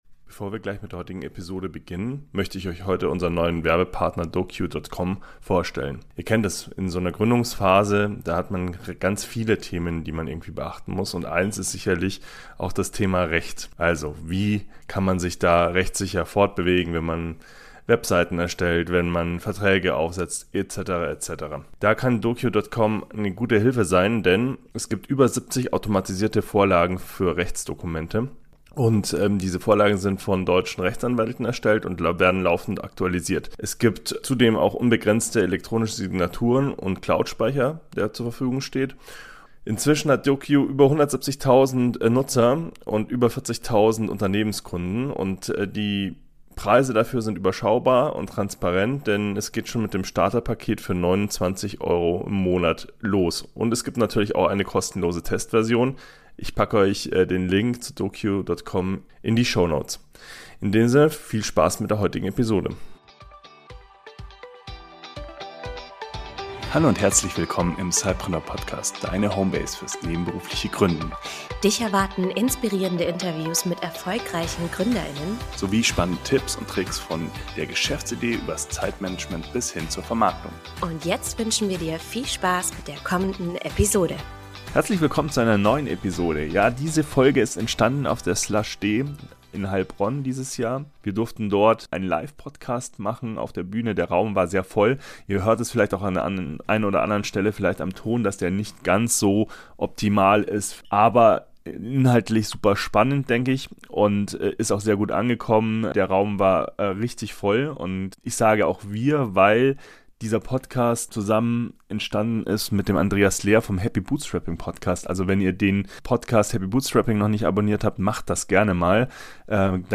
In dieser Live-Episode, aufgenommen auf der Slush’D